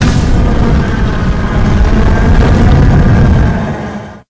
flamethrower_shot_07.wav